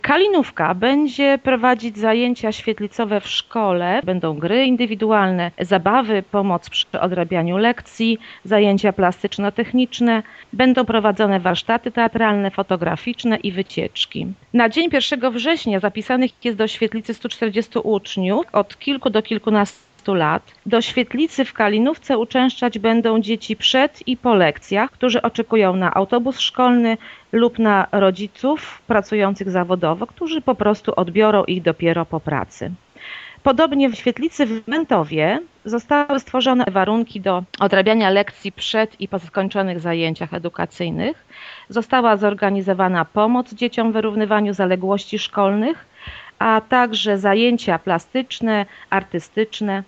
Po około 19 tysięcy złotych otrzymają stowarzyszenia: "Nasza szkoła" przy Zespole Szkół w Kalinówce i "Szkoła marzeń" przy Zespole Szkół w Mętowie - informuje zastępca wójta Urszula Paździor: